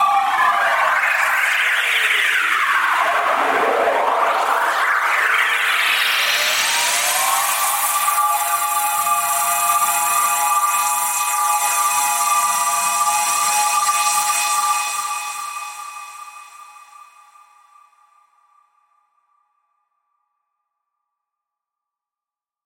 我的声音" dubsiren
描述：Dub siren
标签： 警笛声 丛林 配音 雷鬼
声道立体声